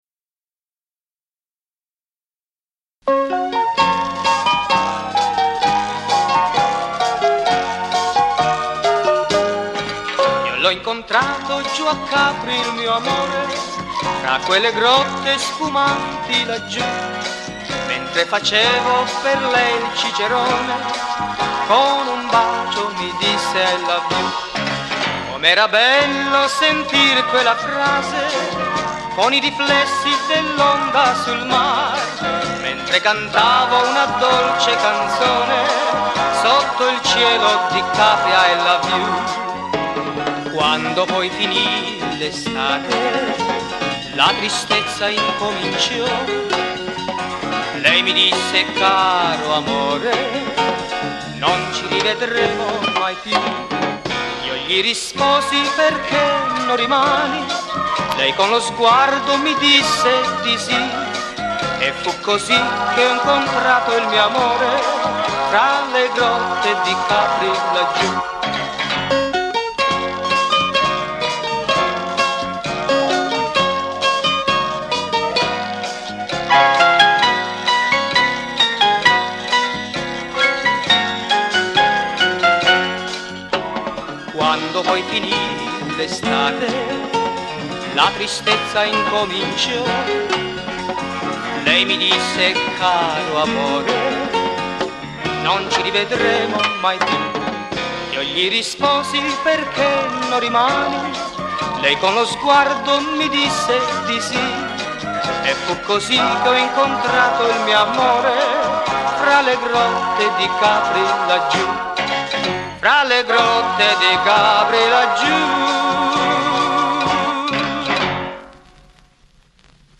DRUMS
GUITARS
TASTIERE